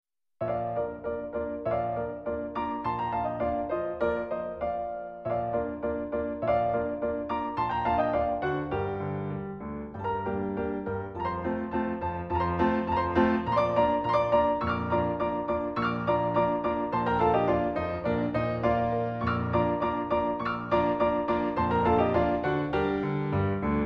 Classical Music Sound Libraryを使用しています。